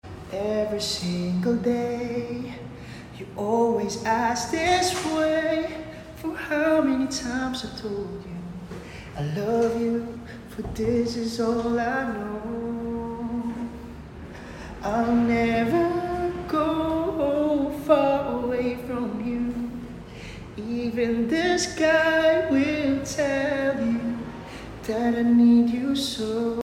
grabe sa lamig ng boses 🧖
hi beb. harana mode ah 🥰😘